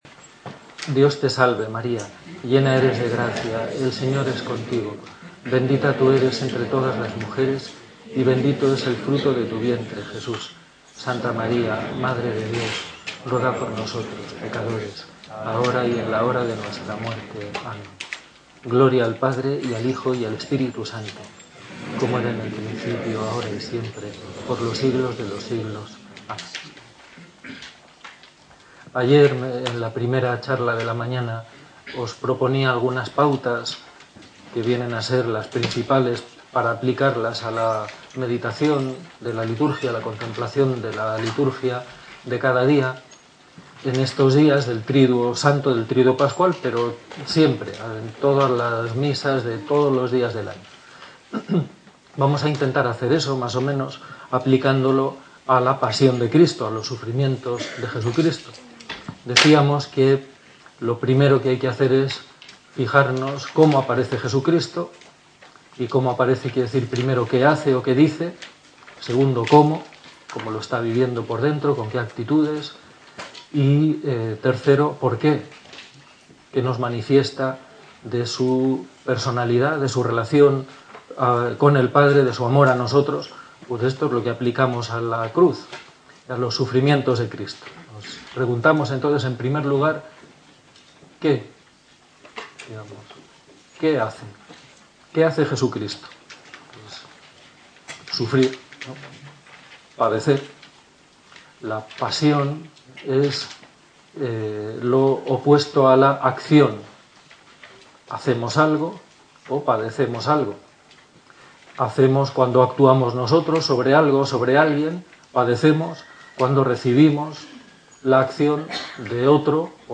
Charla de la mañana